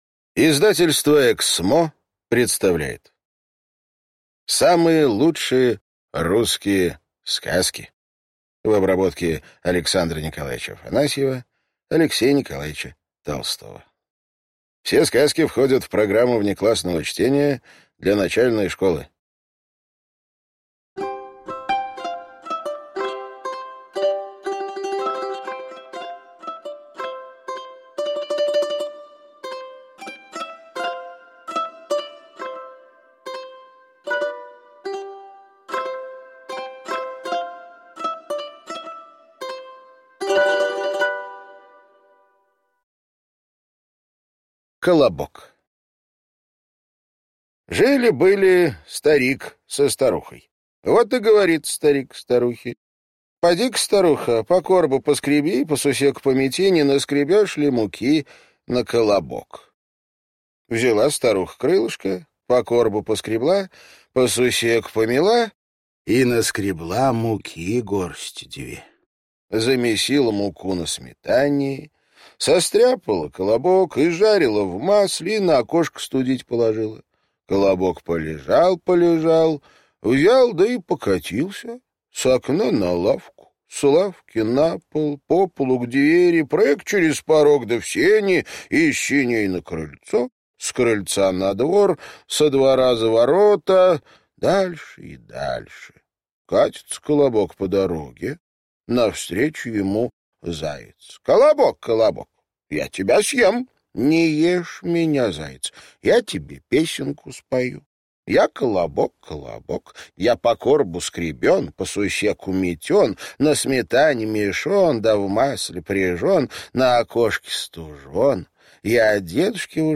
Аудиокнига Самые лучшие русские сказки | Библиотека аудиокниг